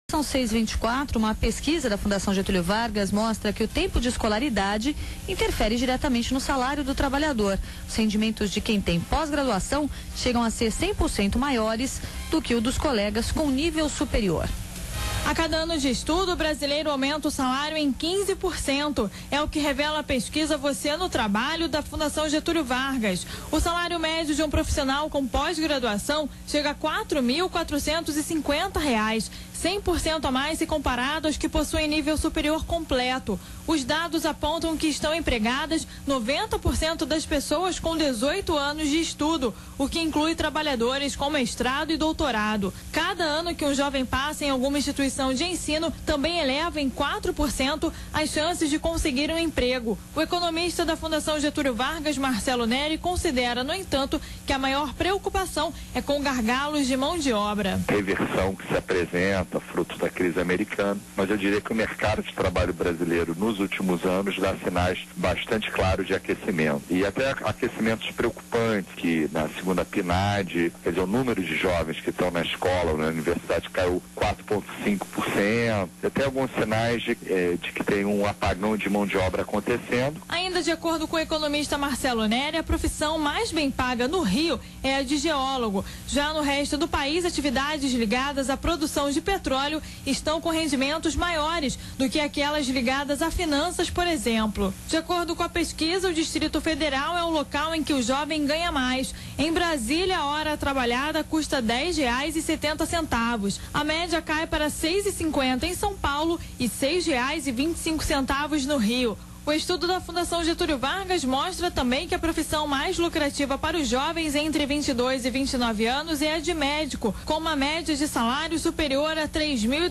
Outubro/2008 Pesquisa da Fundação Getúlio Vargas mostra que o tempo de escolaridade interfere diretamente no salário do trabalhador Meio: Rádio Band News - RJ Mídia: Rádio Temas / Subtemas Desenv.